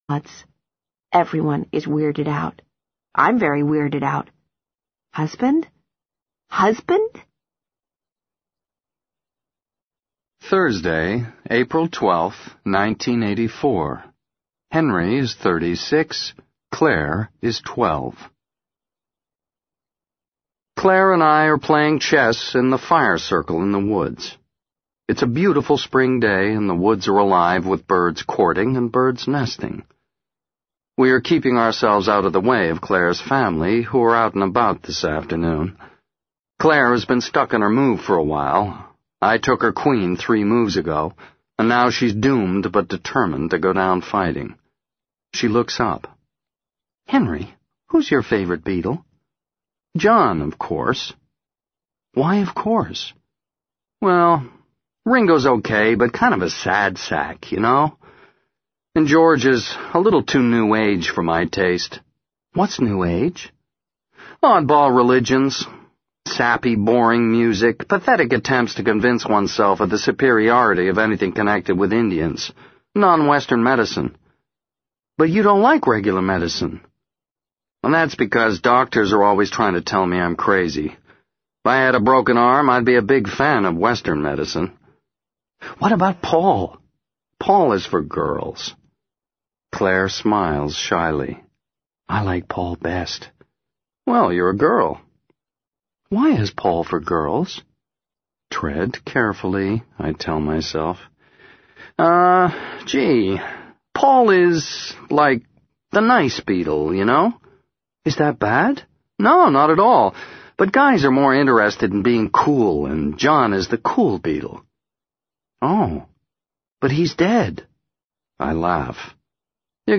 在线英语听力室【时间旅行者的妻子】55的听力文件下载,时间旅行者的妻子—双语有声读物—英语听力—听力教程—在线英语听力室